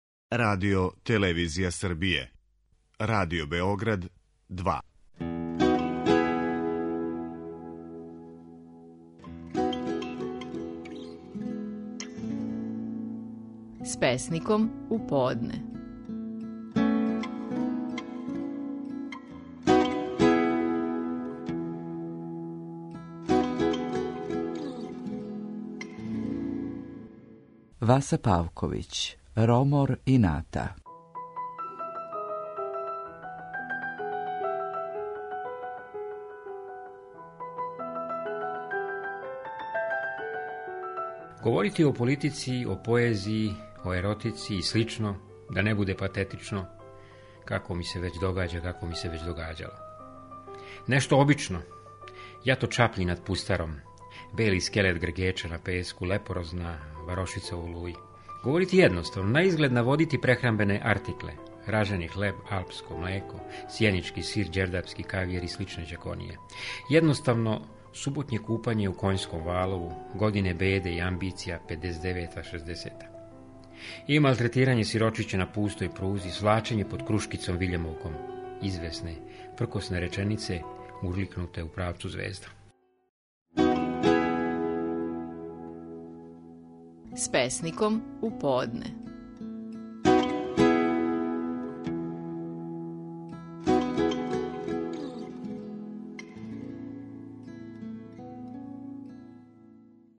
Стихови наших најпознатијих песника, у интерпретацији аутора.
Васа Павковић говори песму „Ромор ината".